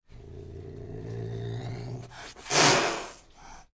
Шипящий звук каракала